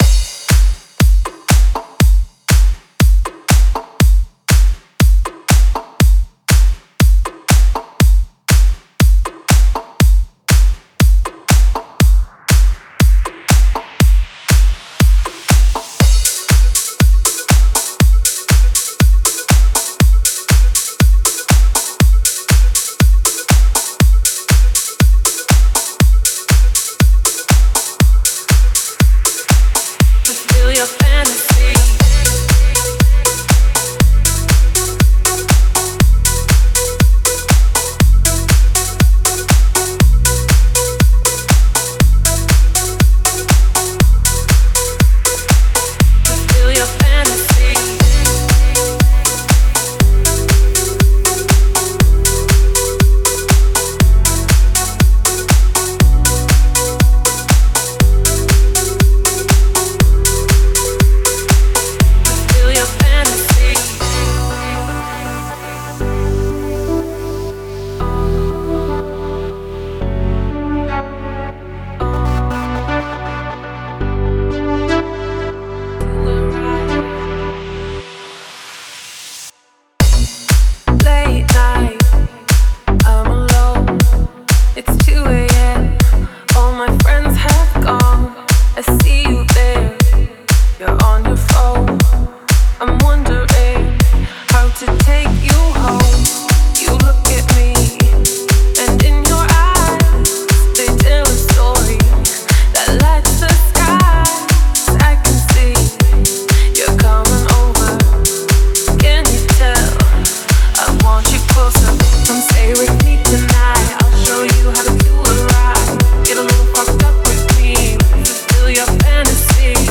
это динамичная композиция в жанре электронной музыки